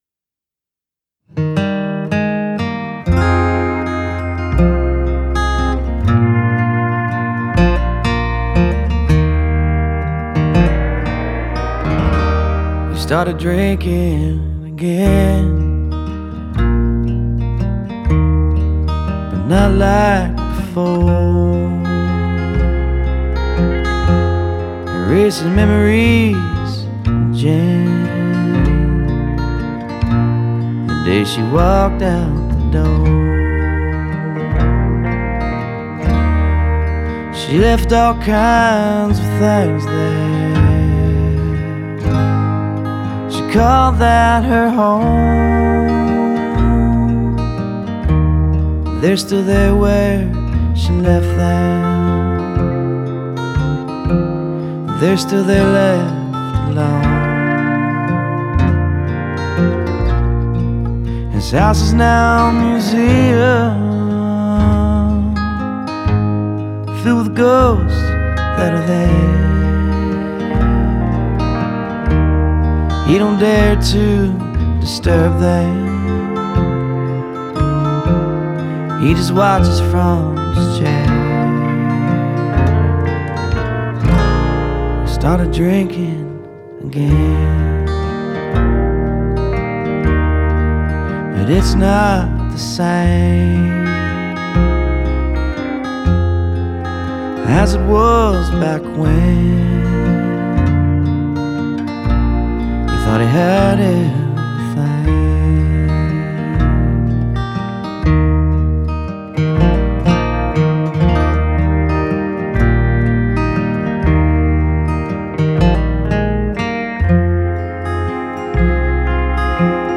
Original classic country tune